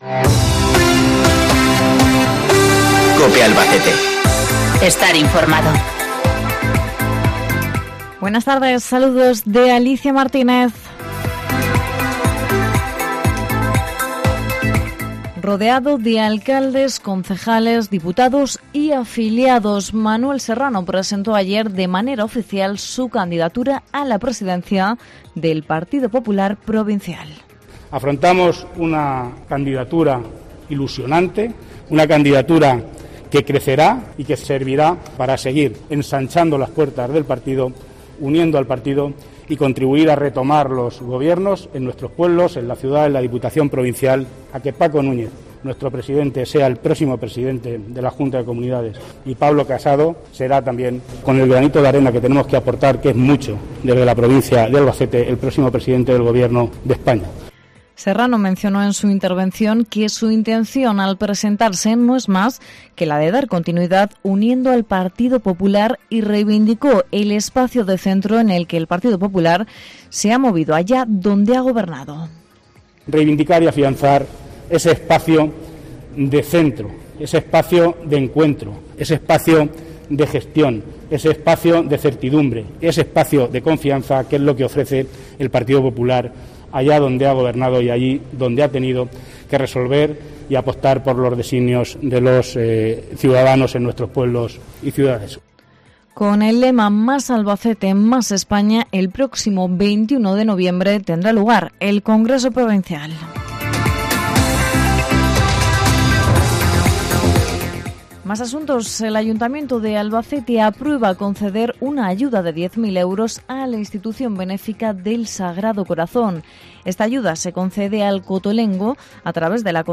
INFROMATIVO LOCAL 23 DE OCTUBRE